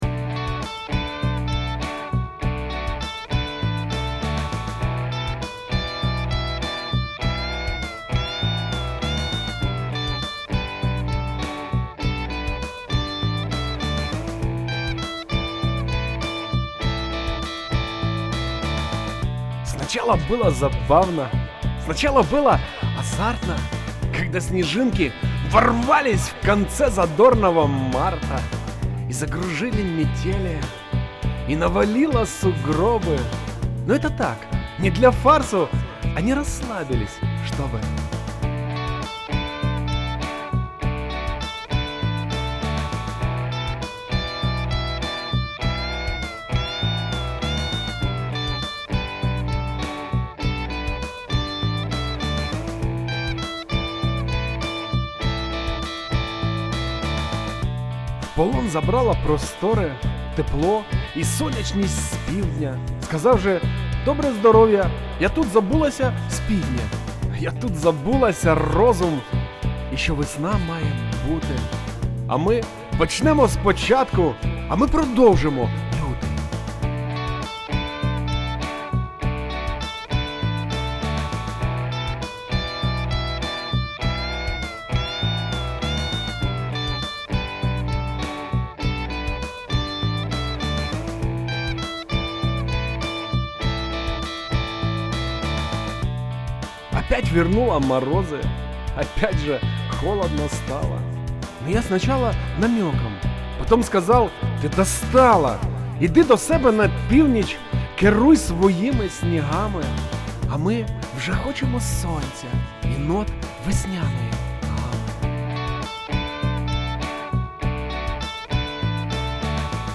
Рубрика: Поезія, Лірика